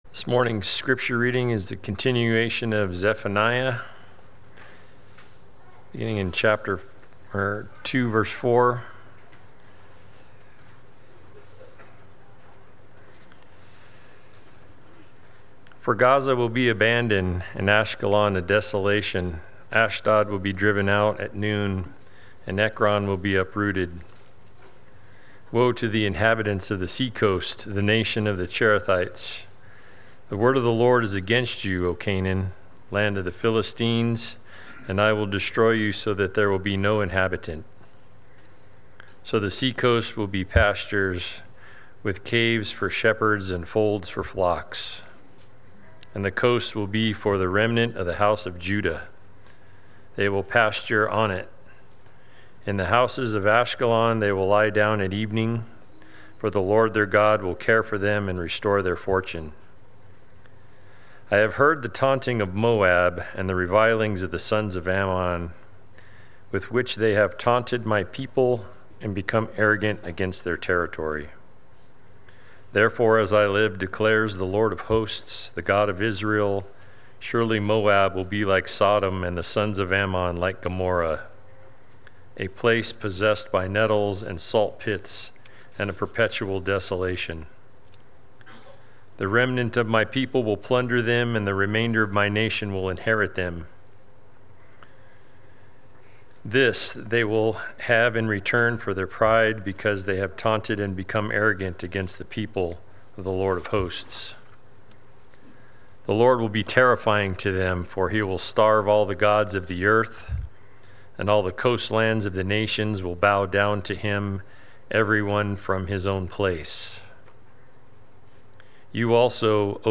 Play Sermon Get HCF Teaching Automatically.
God’s Righteous Judgement Sunday Worship